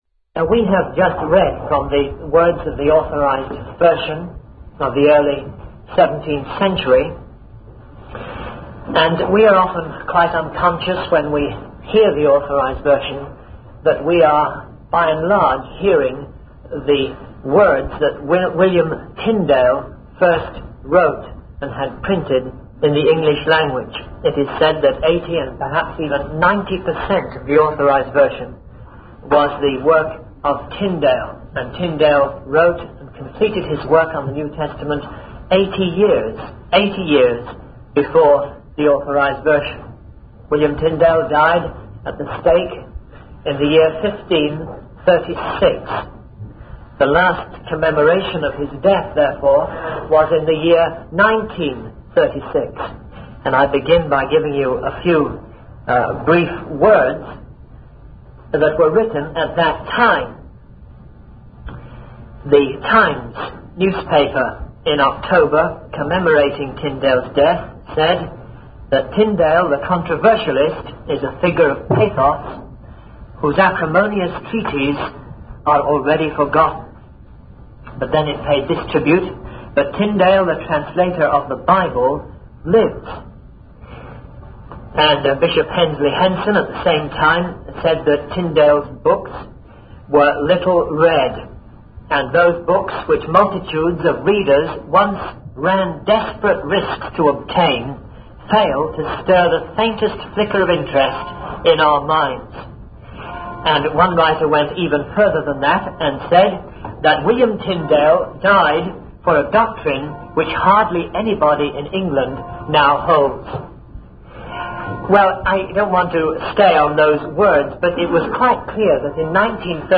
In this sermon, the speaker discusses the life and work of William Tyndale, a key figure in the Reformation. Tyndale was a scholar who translated the New Testament into English in 1526, and later proceeded to translate other books of the Bible.